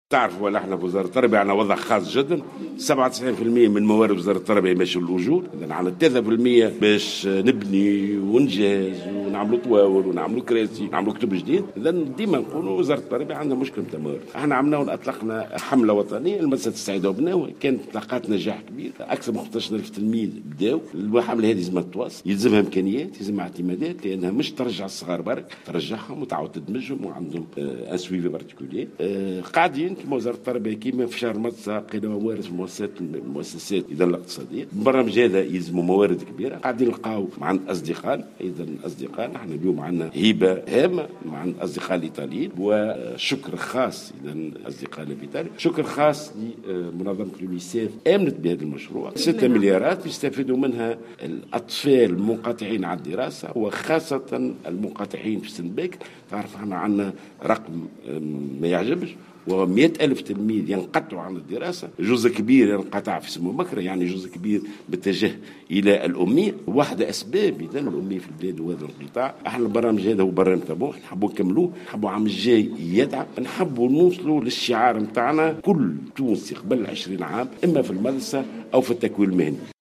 وقال وزير التربية ناجي جلول في تصريح